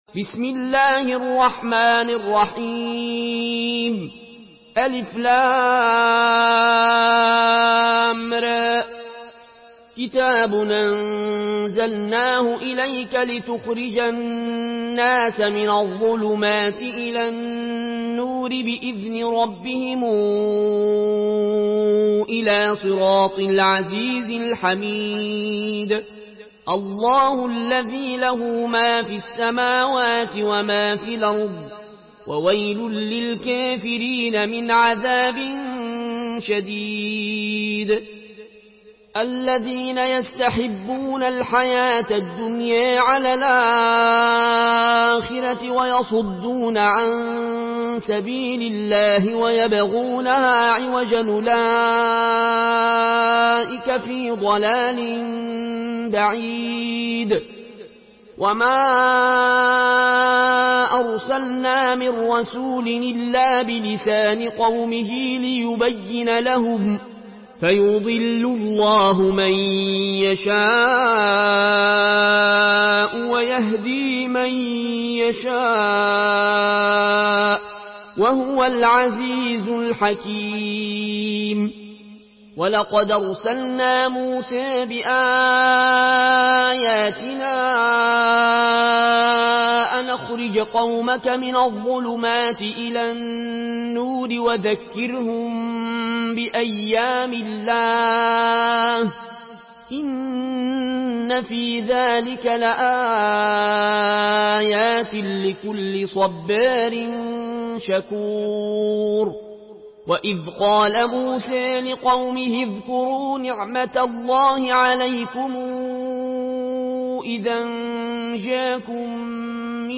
Murattal Warsh An Nafi From Al-Azraq way